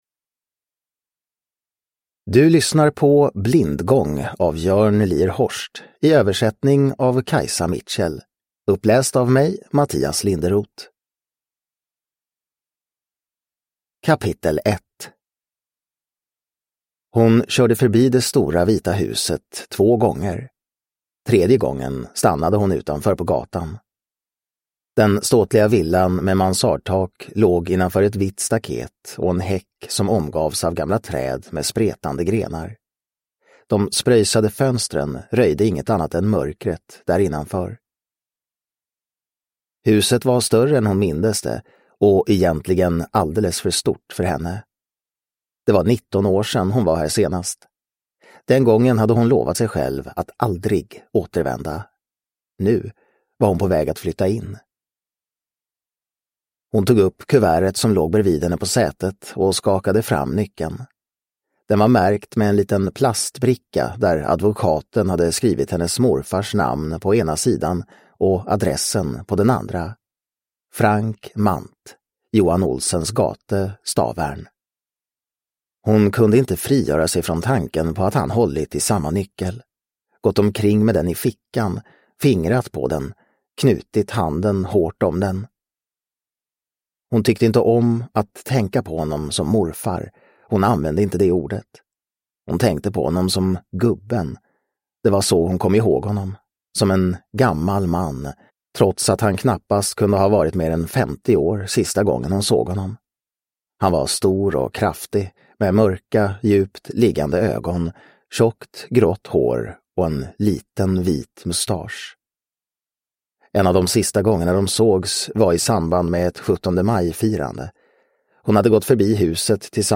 Blindgång – Ljudbok – Laddas ner